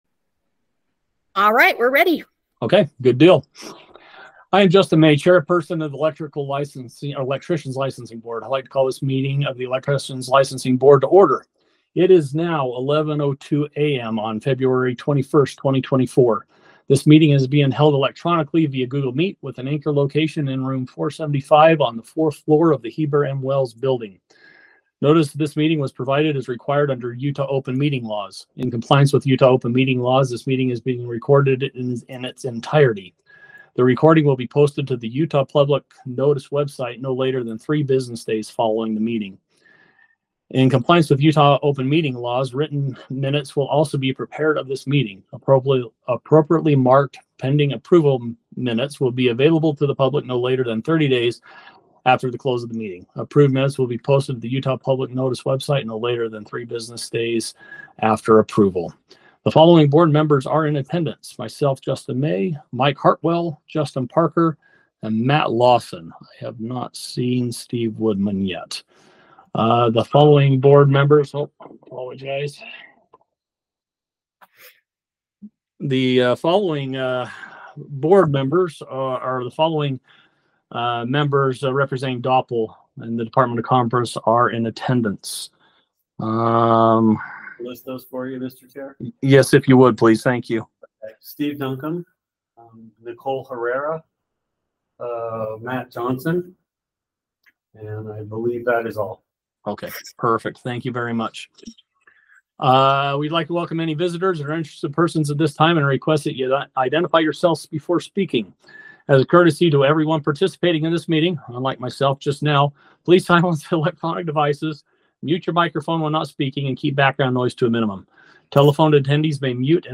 Electrician Licensing Board Meeting